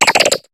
Cri de Flingouste dans Pokémon HOME.